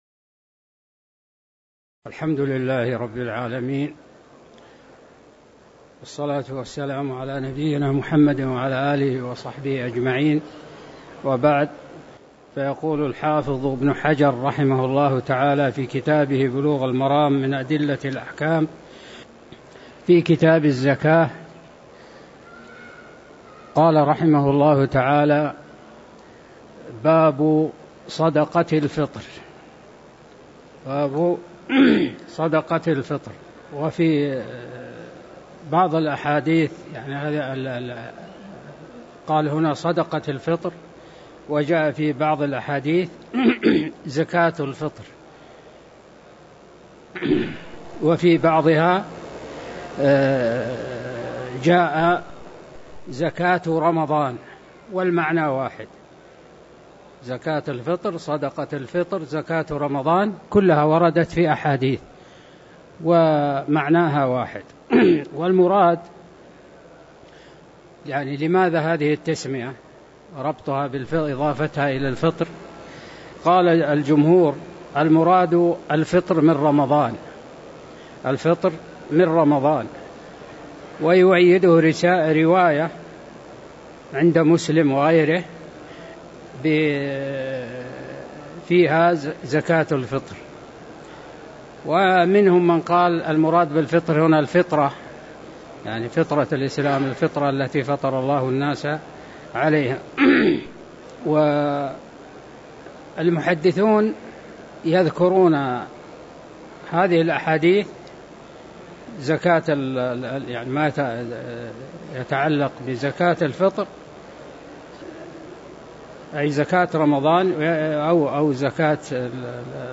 تاريخ النشر ٢ صفر ١٤٤٠ هـ المكان: المسجد النبوي الشيخ